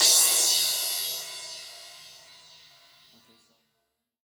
DnBCymbalA-02.wav